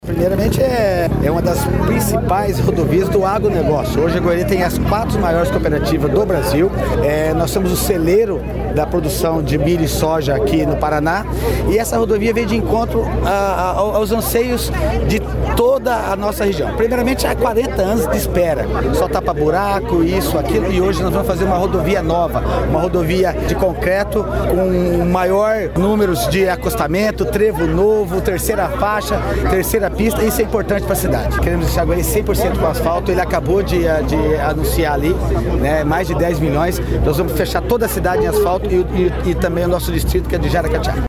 Sonora do prefeito de Goioerê, Betinho Lima, sobre o início da restauração em concreto da PR-180 entre a cidade e Quarto Centenário